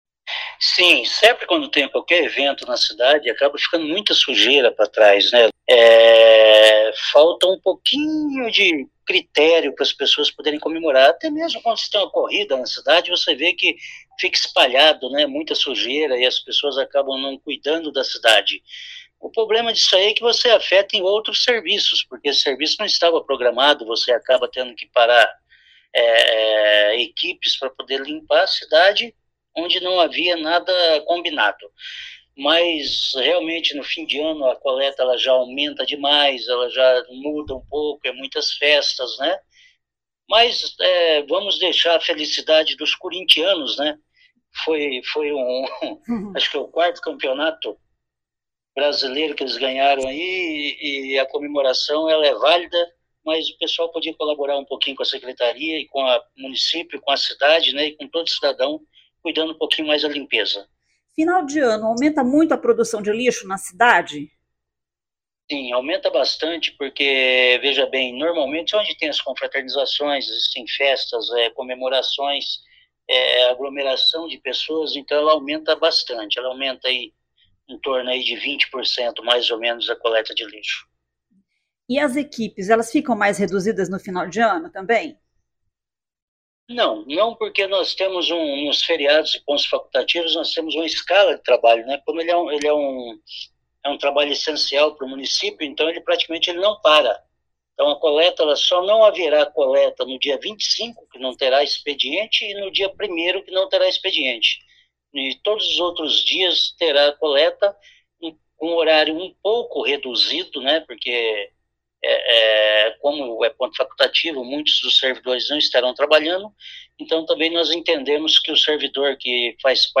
Ouça o que diz o secretário Vagner Mussio.